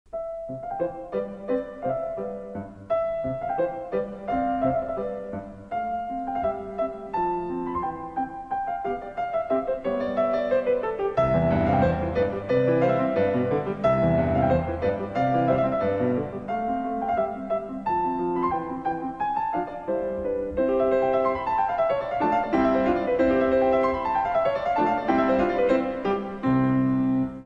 Uitgevoerd door Krystian Zimerman.
Mozart-Sonate_C-3-Satz-Zimerman_Anf.mp3